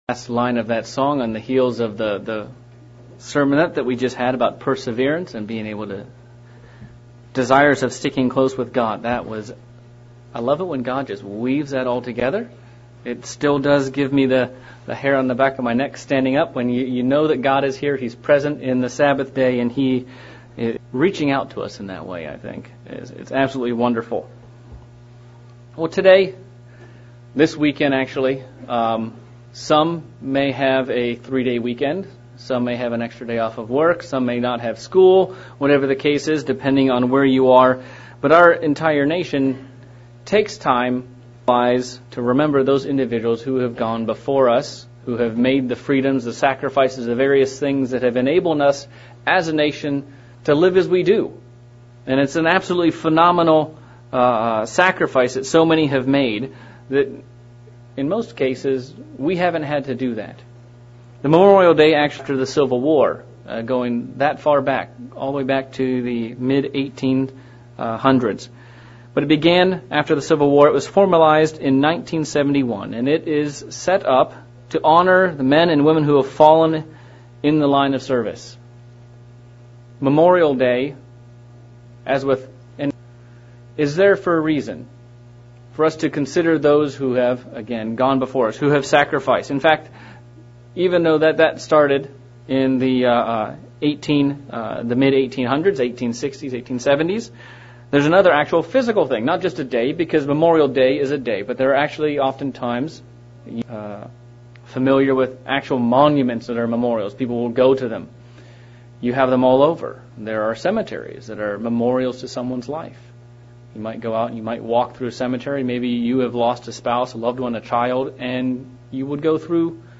Sermon looking at God's use of memorials in the Bible and what he intended them to be used for.